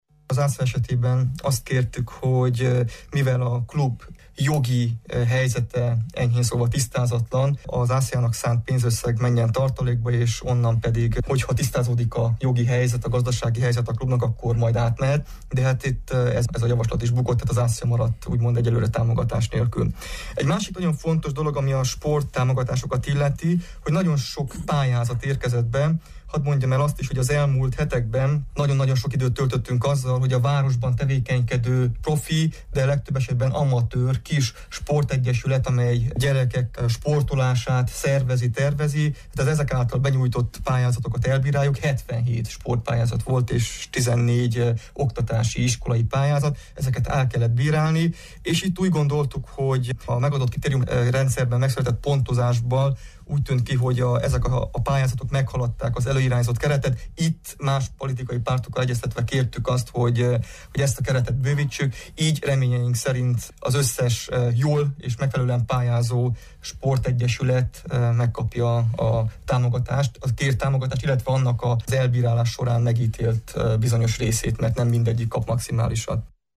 Nem kap támogatást a marosvásárhelyi önkormányzattól az ASA labdarúgóklub. A tegnapi tanácsülésen történtekről Novák Csaba Zoltán az RMDSZ tanácsosa beszélt rádiónknak.